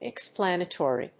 音節ex・plan・a・to・ry 発音記号・読み方
/ɪksplˈænət`ɔːri(米国英語), ɪkˈsplanət(ə)ri(英国英語)/